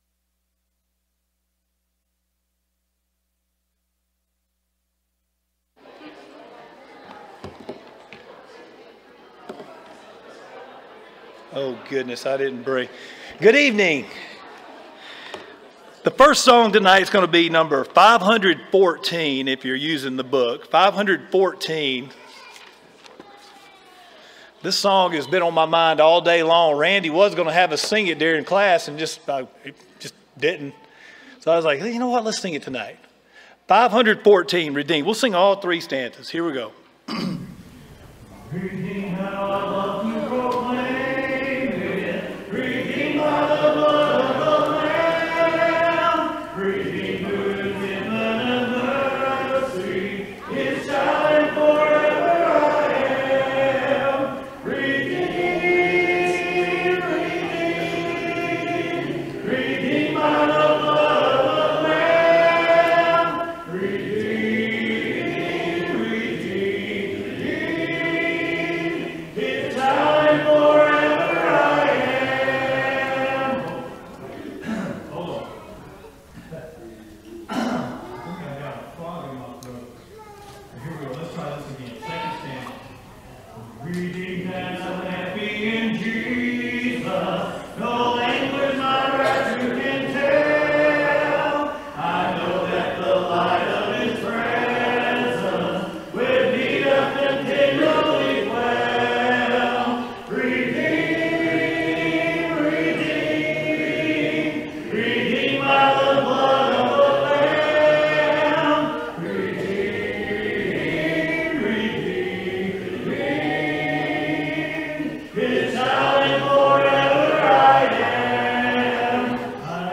” Ephesians 5:15-17, English Standard Version Series: Sunday PM Service